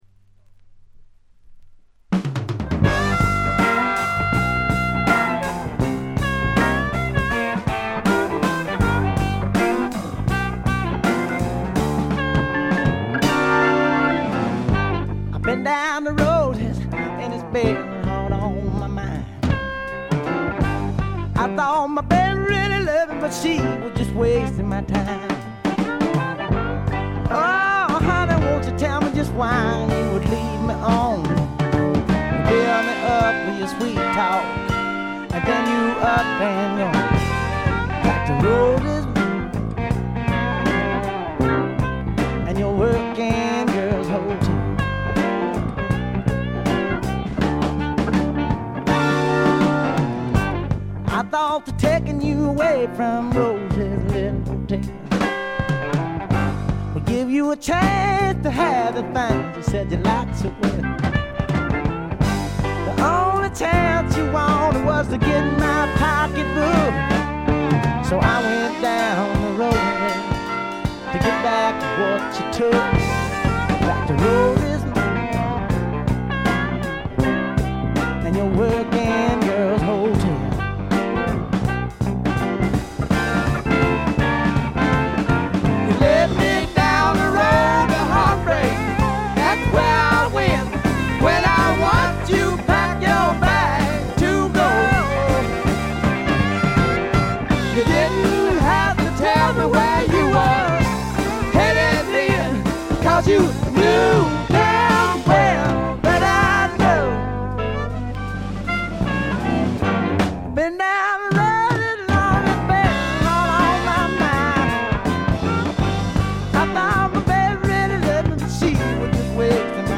部分試聴ですがほとんどノイズ感無し。
びしっと決まった硬派なスワンプ・ロックを聴かせます。
試聴曲は現品からの取り込み音源です。
Recorded at Paramount Recording Studio.